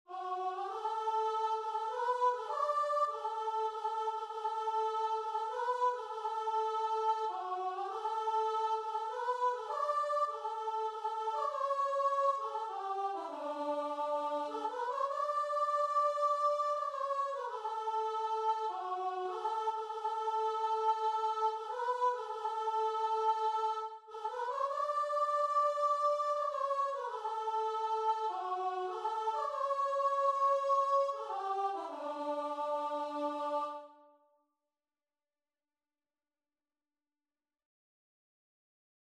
Christian Christian Lead Sheets Sheet Music I Will Sing the Wondrous Story
D major (Sounding Pitch) (View more D major Music for Lead Sheets )
3/4 (View more 3/4 Music)
Classical (View more Classical Lead Sheets Music)